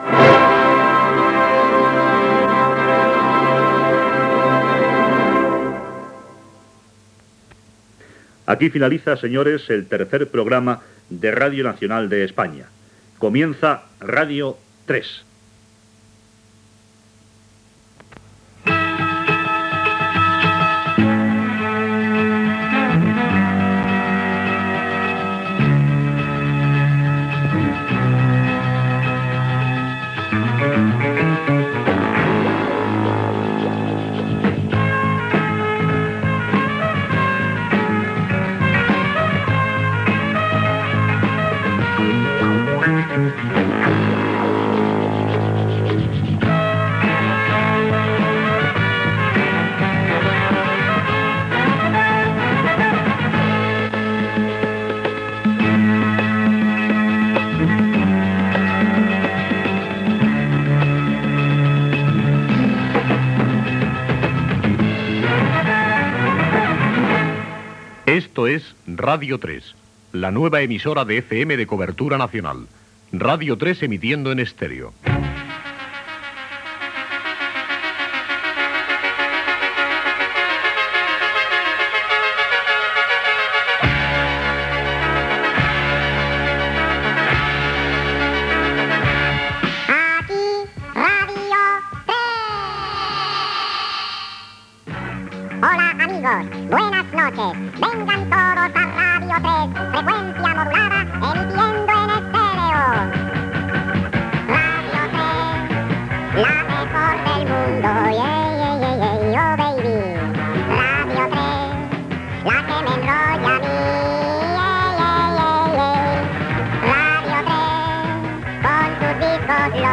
Final del Tercer Programa, indicatiu de Radio 3, cançó de l'emissora i inici de "Rock 3".
Musical
FM